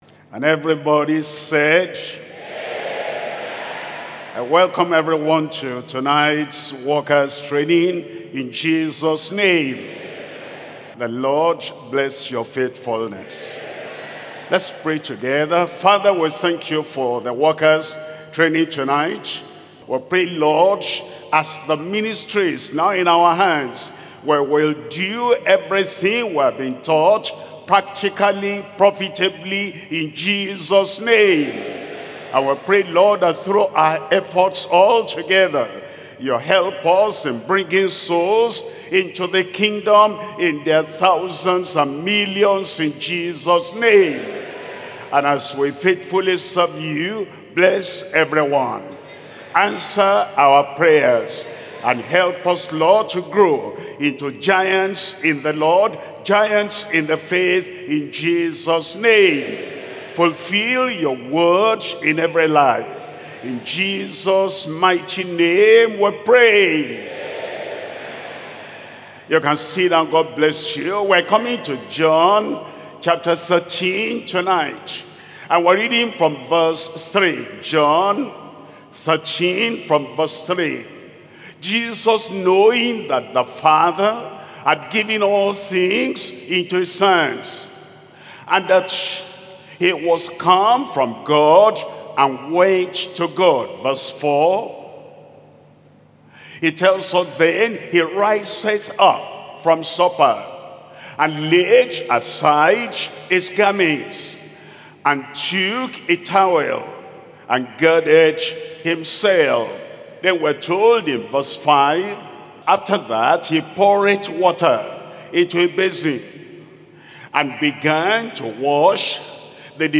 Sermons - Deeper Christian Life Ministry
2026 Workers and Leaders Training